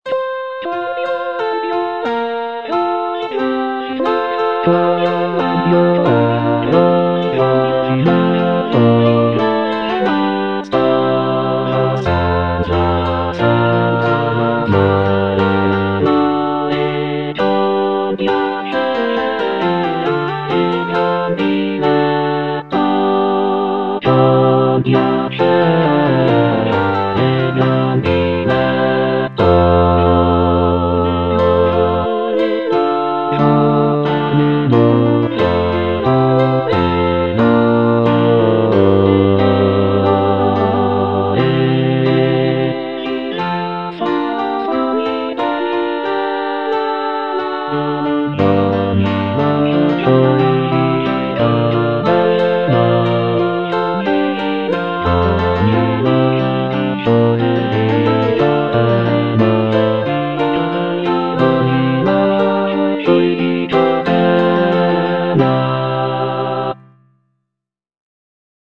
G. GABRIELI - QUAND'IO ERO GIOVINETTO Quand'io ero giovinetto - Bass (Emphasised voice and other voices) Ads stop: auto-stop